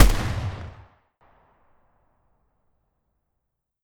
AR2_Shoot 04.wav